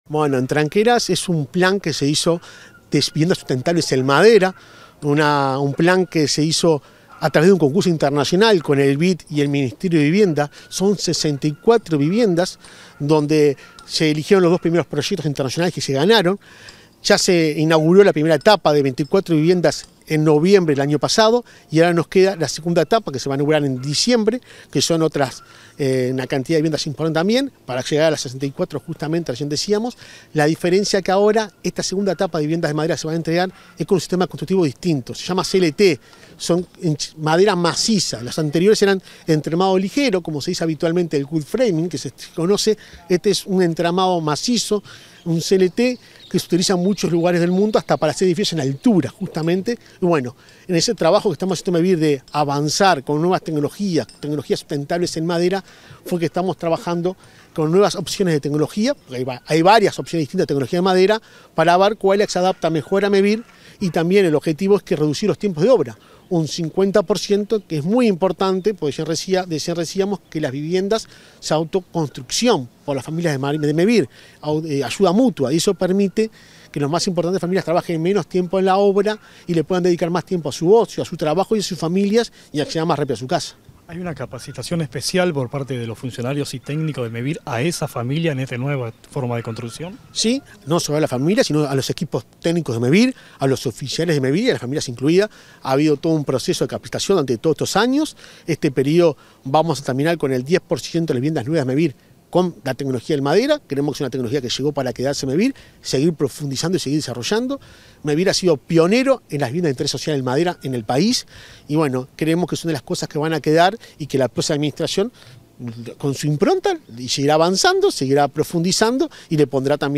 Declaraciones del presidente de Mevir, Juan Pablo Delgado